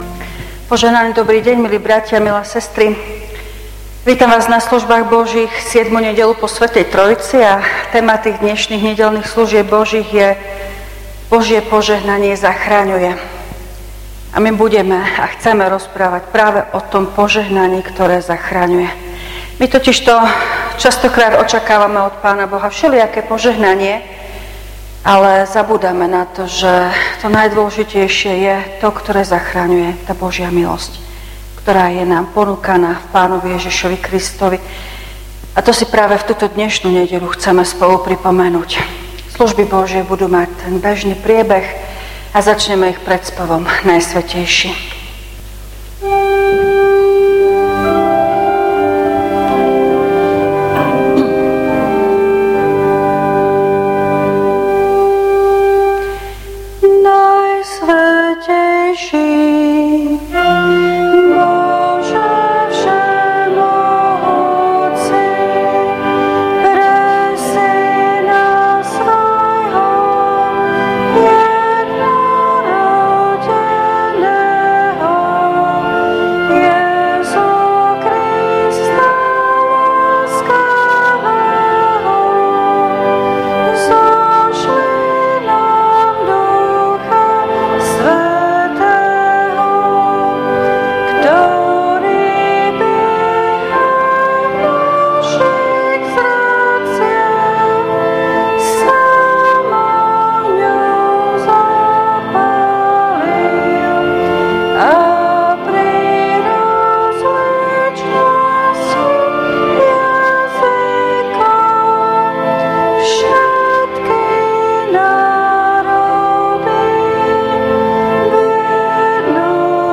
V nasledovnom článku si môžete vypočuť zvukový záznam zo služieb Božích – 7. nedeľa po Sv. Trojici.
Piesne: 187, 279, 199, 622, A51.